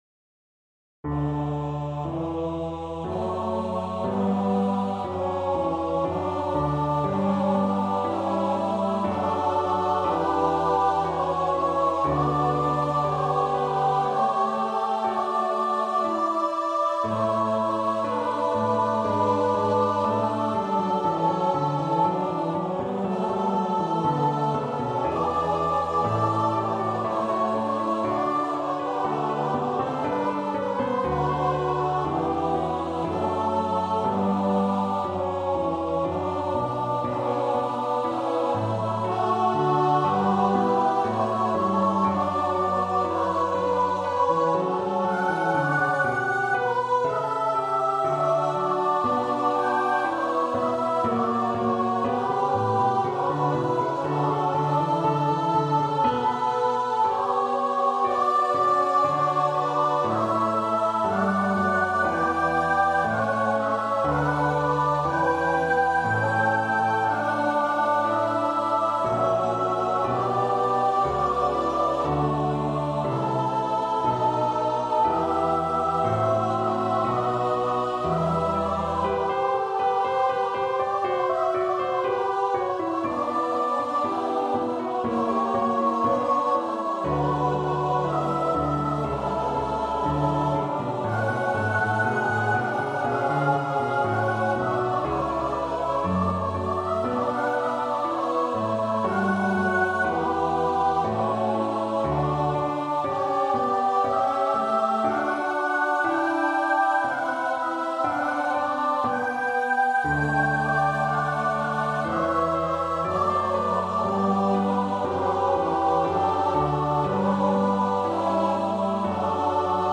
Free Sheet music for Choir (SATB)
Choir  (View more Intermediate Choir Music)
Classical (View more Classical Choir Music)